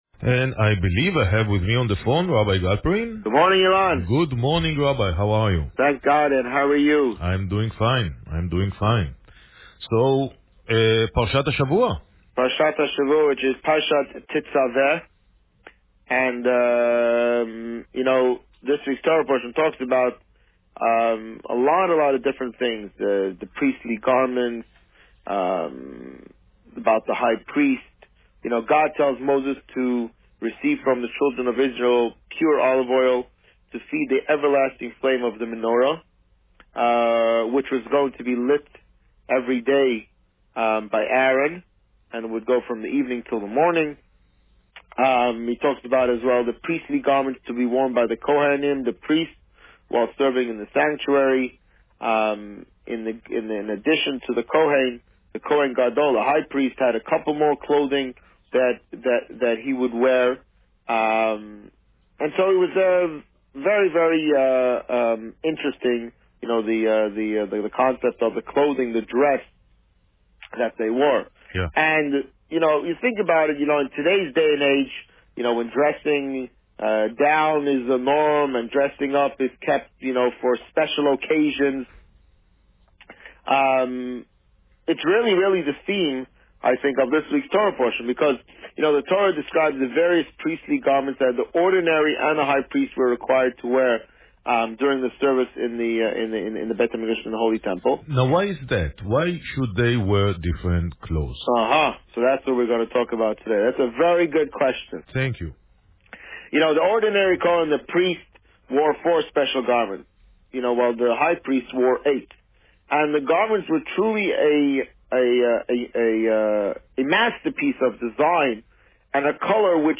This week, the Rabbi spoke about Parsha Tetzaveh and briefly mentioned the upcoming Purim party at the JCC. Listen to the interview here.